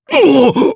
Phillop-Vox_Damage_jp_02.wav